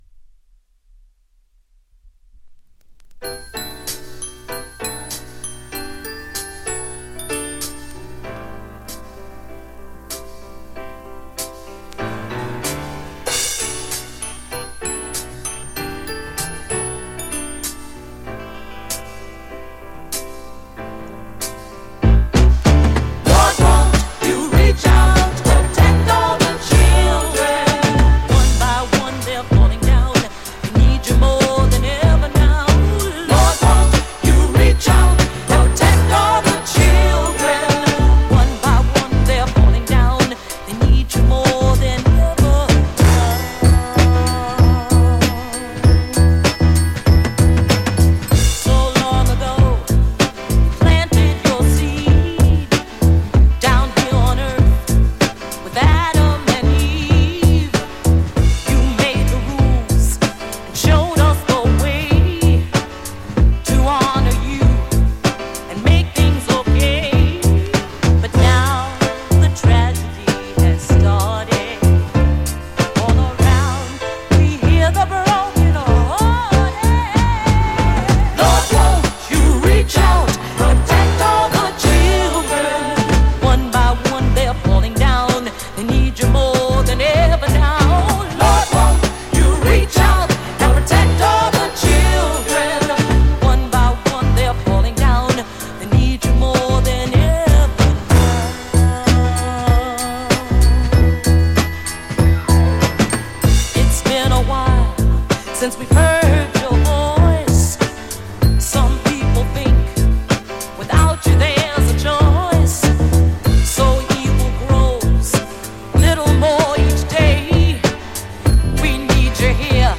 ジャンル(スタイル) DISCO / SOUL / FUNK